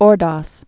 (ôrdŏs)